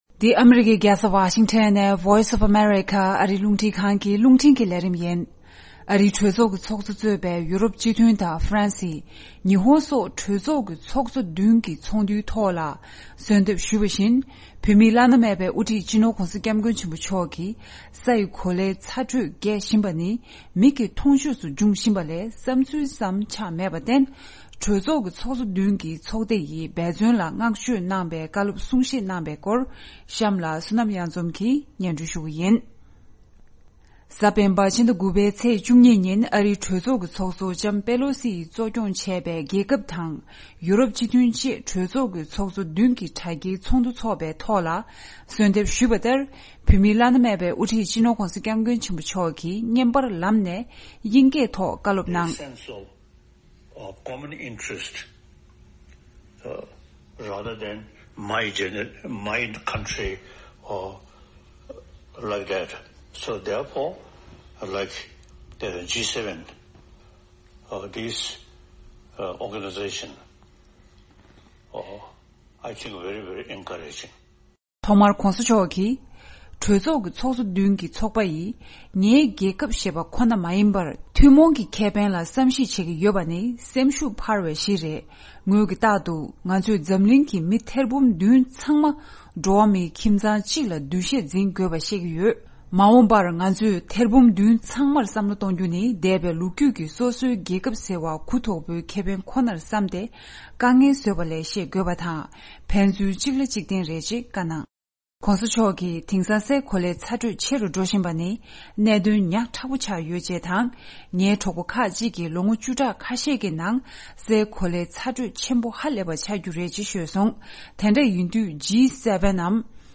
གནས་ཚུལ་སྙན་སྒྲོན